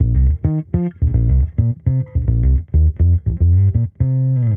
Index of /musicradar/sampled-funk-soul-samples/105bpm/Bass
SSF_PBassProc1_105C.wav